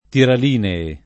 tiralinee [ tiral & ne-e ] s. m.; inv.